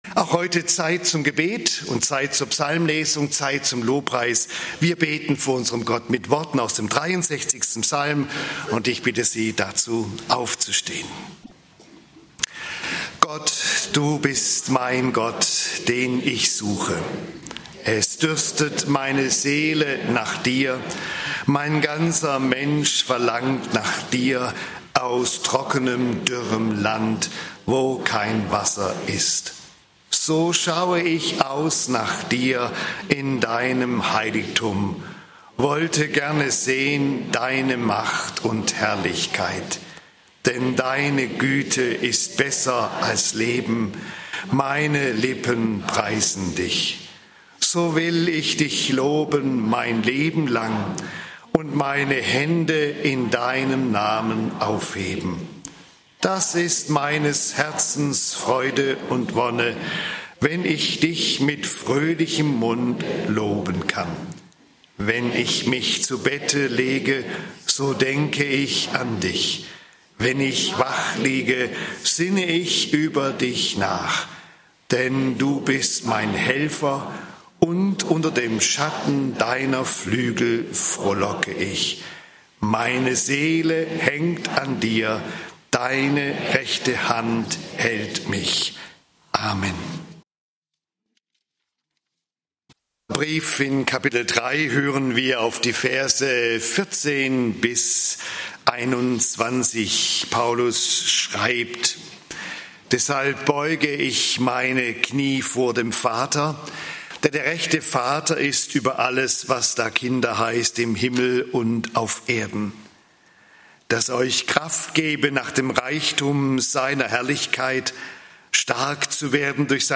Gottes unbegreifliche Liebe begreifen (Eph. 3, 14-21) - Gottesdienst